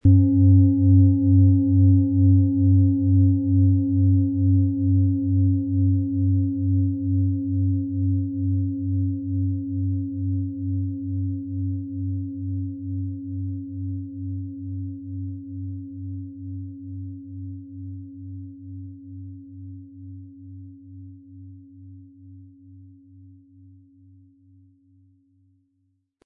Planetenschale® Offener entspannter Brustbereich & Verwurzele Dich mit OM-Ton & Tageston, Ø 22,5 cm, 1100-1200 Gramm inkl. Klöppel
• Tiefster Ton: Tageston
Um den Originalton der Schale anzuhören, gehen Sie bitte zu unserer Klangaufnahme unter dem Produktbild.
PlanetentöneOM Ton & Tageston
MaterialBronze